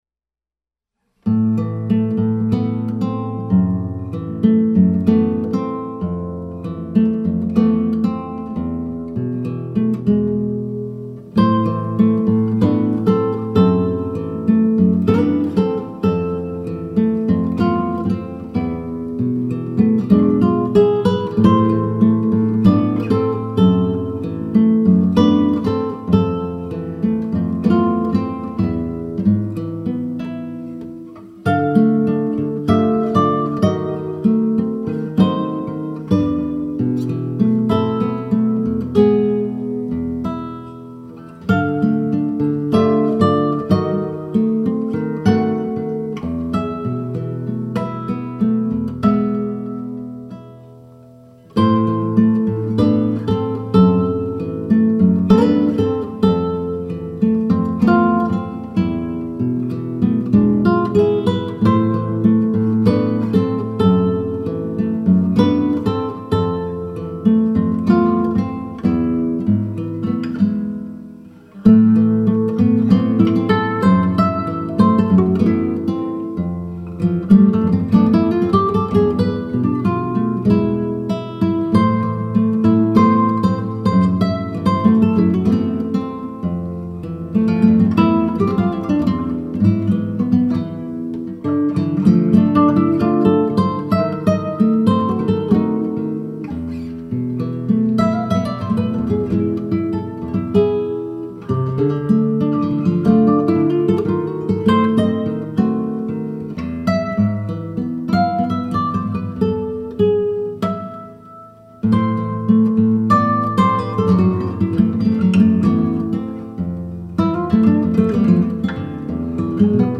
موسیقی بی کلام , آرامش بخش , گیتار